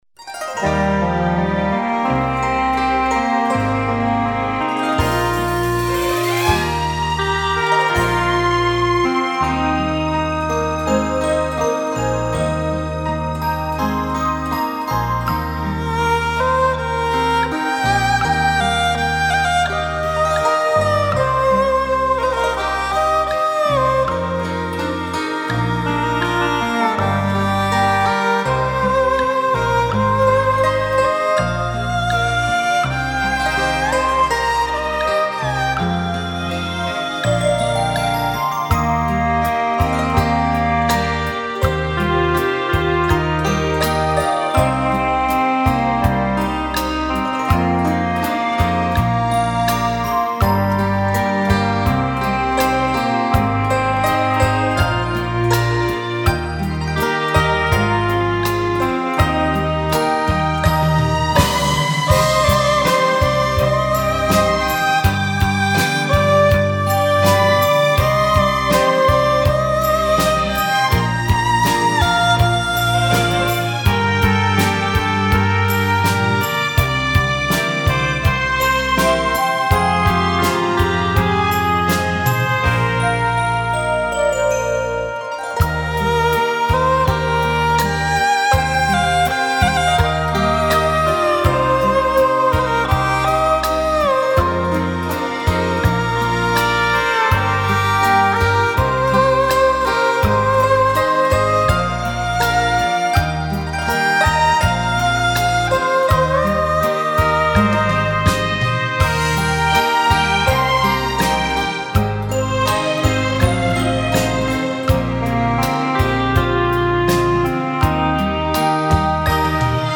悠扬舒缓的音乐